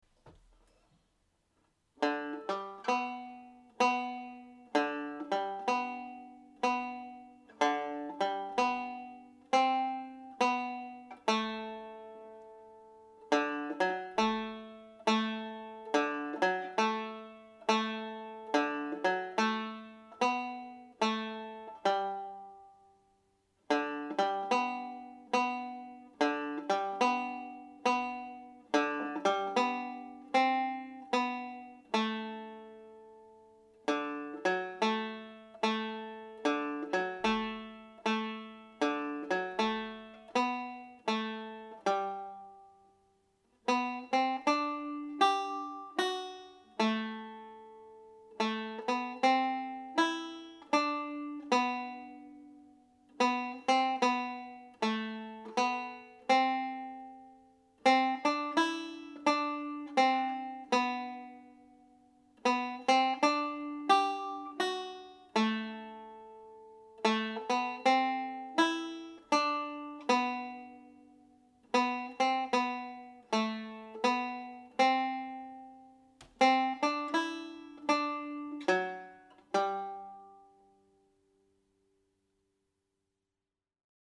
Shoe the Donkey played slowly